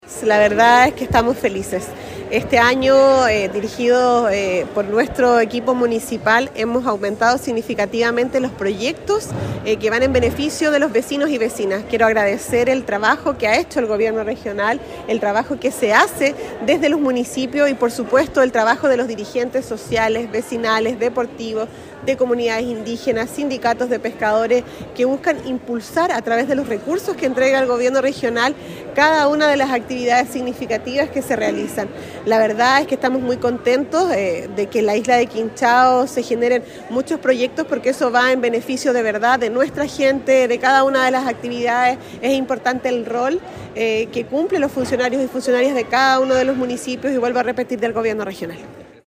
Hasta el gimnasio fiscal de Achao se trasladó la alcaldesa Javiera Yáñez junto a las entidades curacanas favorecidas con estos fondos regionales, para participar de la ceremonia bicomunal de la firma de convenios con la presencia del alcalde René Garcés, el Consejero Regional Francisco Cárcamo, y concejales de ambas comunas que estuvieron acompañando a las distintas organizaciones vecinales ganadoras.
En la oportunidad, la alcaldesa Javiera Yáñez indicó: